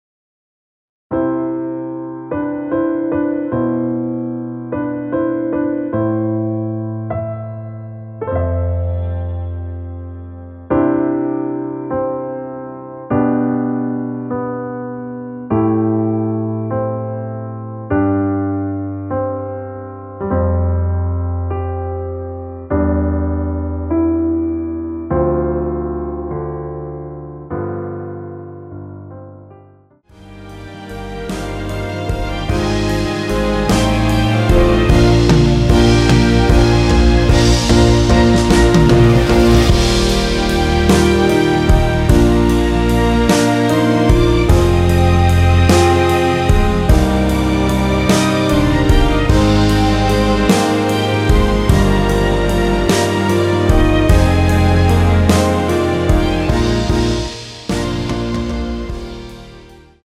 원키에서(-5)내린 MR입니다.
앞부분30초, 뒷부분30초씩 편집해서 올려 드리고 있습니다.
중간에 음이 끈어지고 다시 나오는 이유는